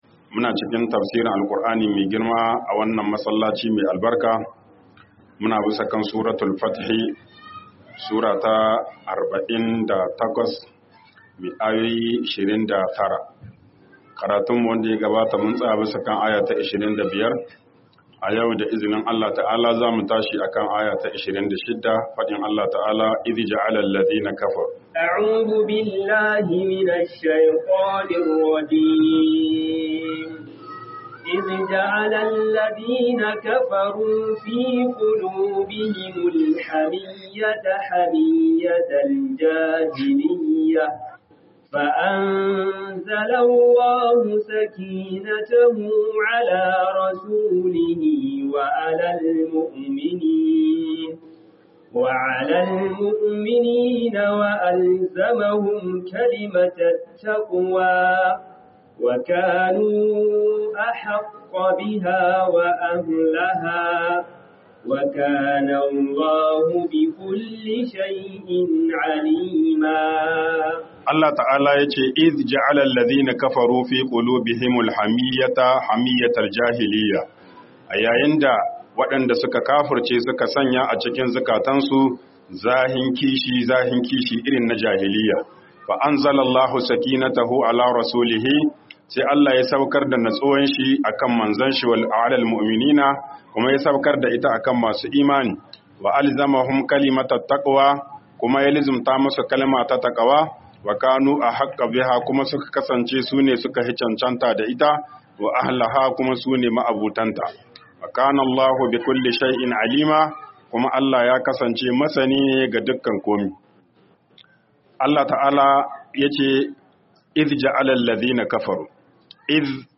TAFSIR RAMADAN MASJID ALSHABAB 05